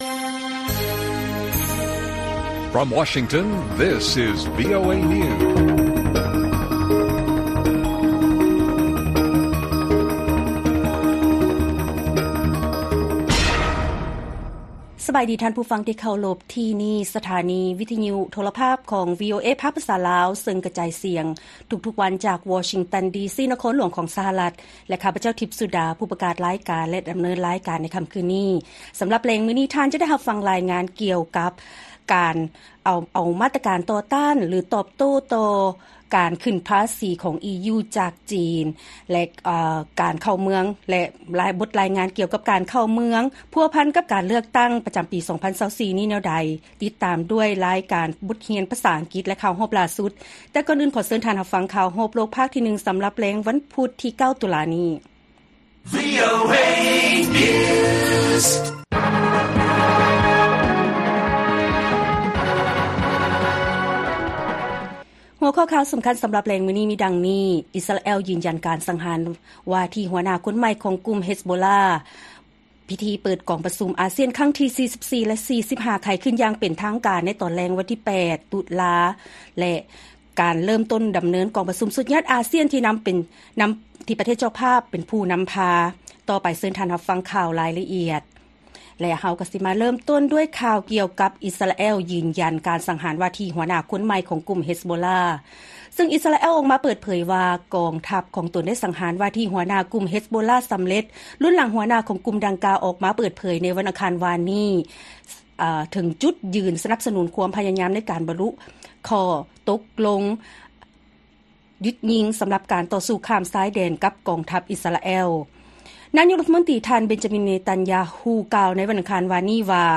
ລາຍການກະຈາຍສຽງຂອງວີໂອເອລາວ: ອິສຣາແອລ ຢືນຢັນການສັງຫານວ່າທີ່ຫົວໜ້າຄົນໃໝ່ຂອງກຸ່ມເຮັສໂບລລາ